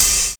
DISCO 1 OH.wav